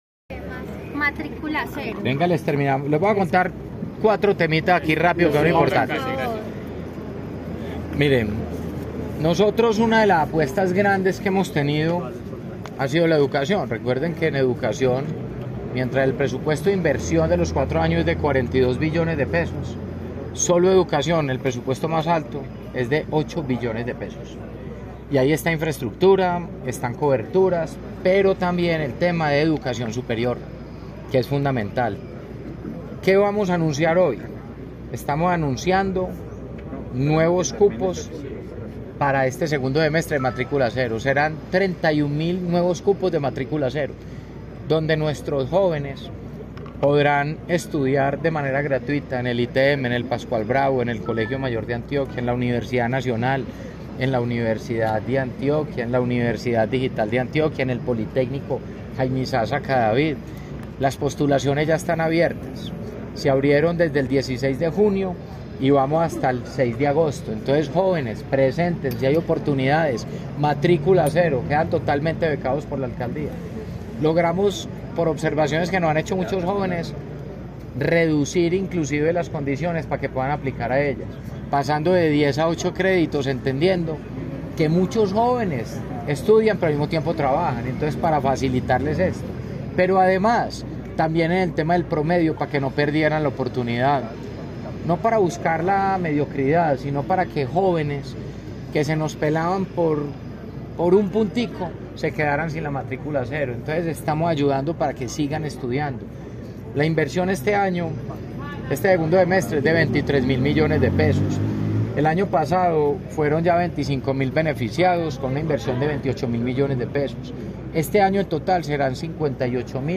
Declaraciones-alcalde-de-Medellin-Federico-Gutierrez-3.mp3